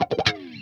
WAHWAHCHUNK2.wav